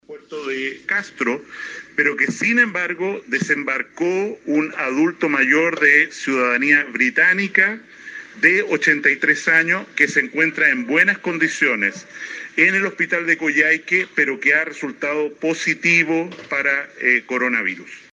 El ministro de salud de la época, Jaime Mañalich, así confirmaba la llegada del buque holandés a nuestras costas con un caso positivo.